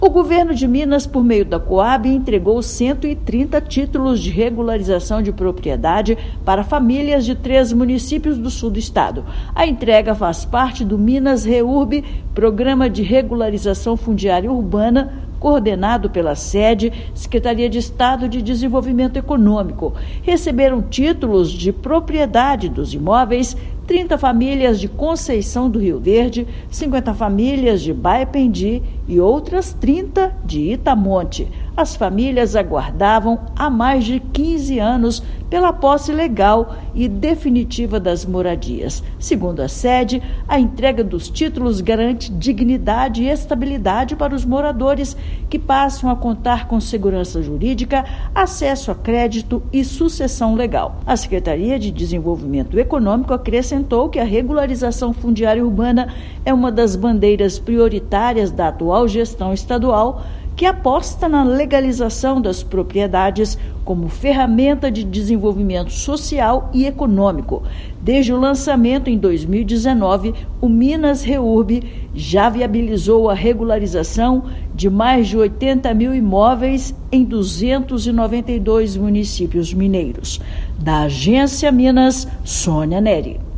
Agência Minas Gerais | [RÁDIO] Governo de Minas realiza entrega de títulos de regularização de propriedade a 130 famílias do Sul do estado
Após 15 anos de espera, moradores de Conceição do Rio Verde, Baependi e Itamonte são contemplados por meio do programa Minas Reurb. Ouça matéria de rádio.